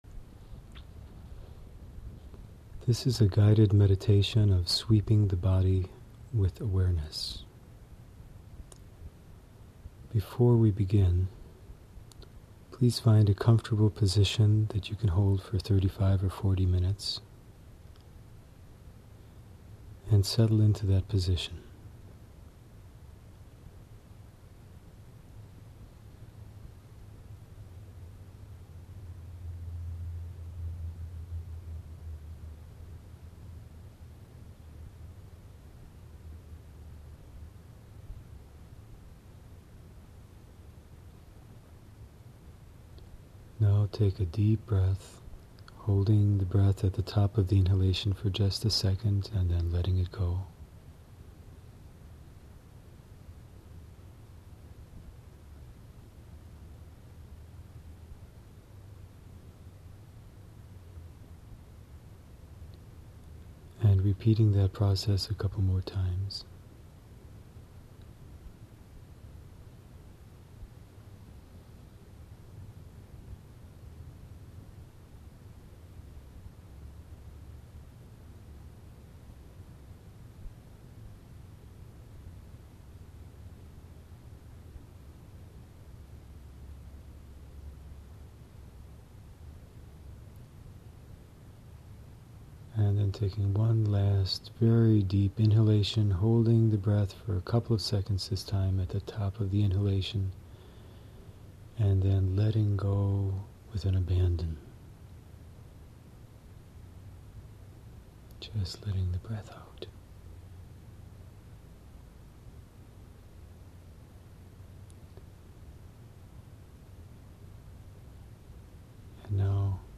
Meditations
sweeping-the-body-meditation-1.mp3